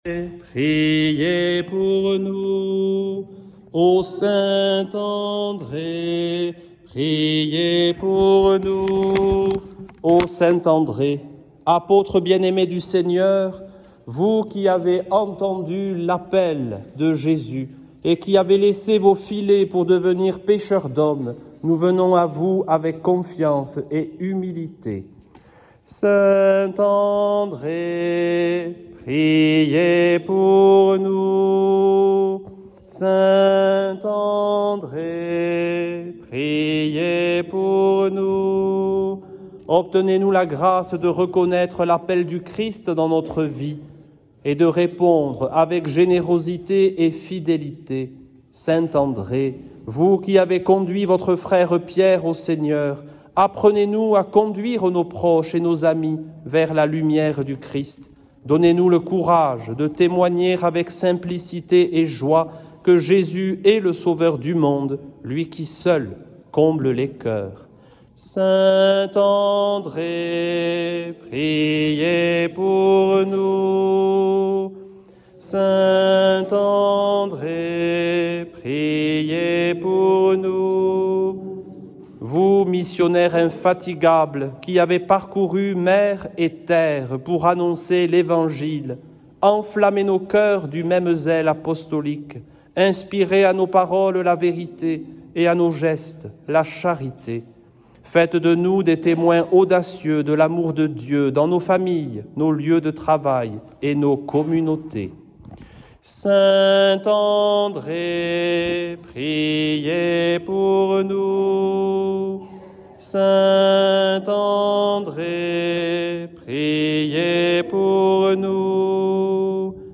priere-saint-andre.mp3